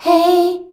HEY     F.wav